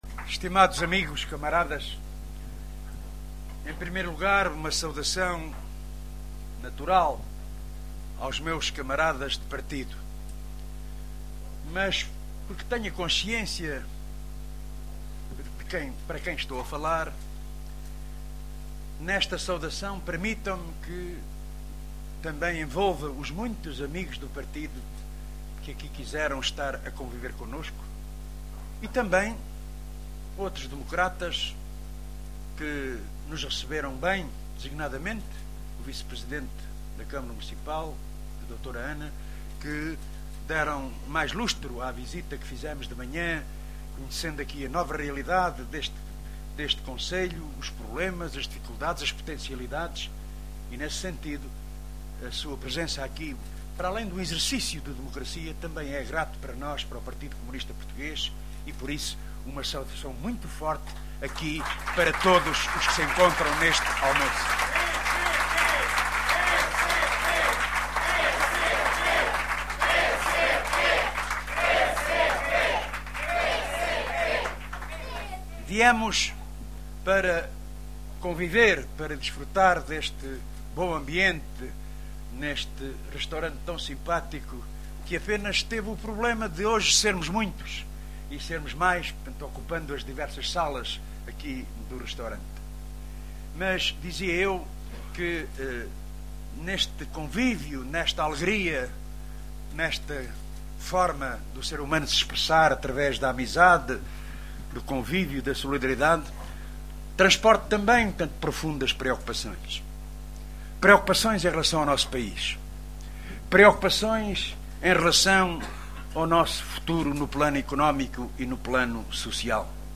Intervenção de Jerónimo de Sousa no almoço convívio em Ourém